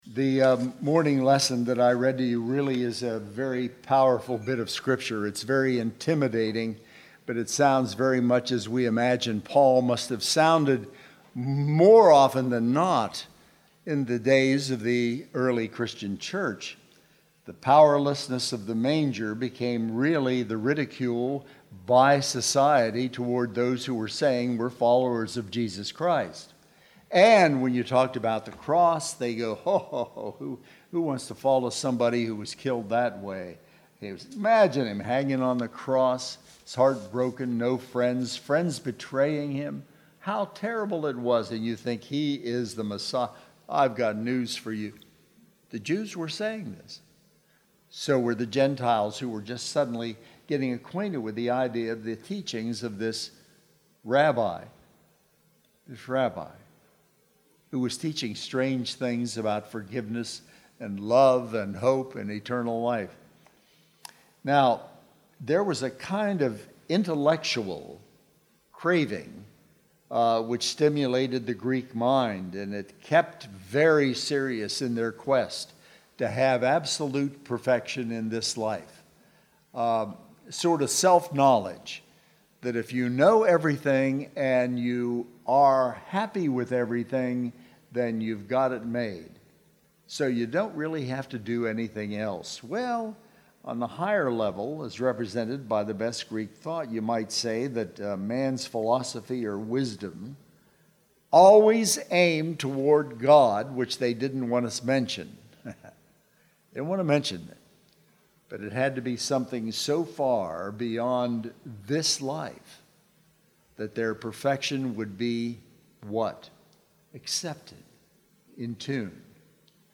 Sermon Tags